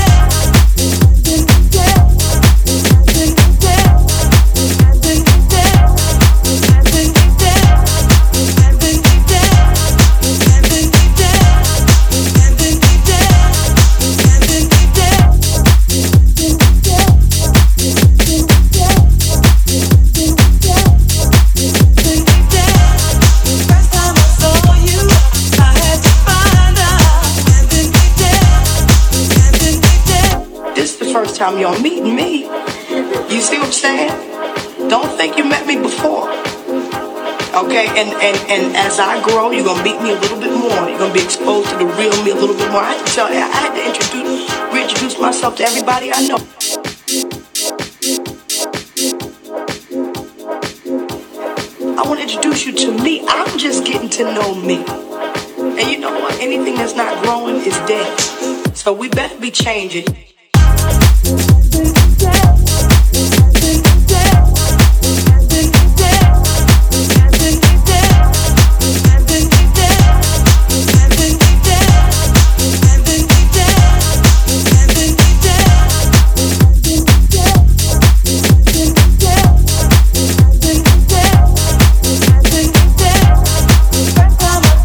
House tracks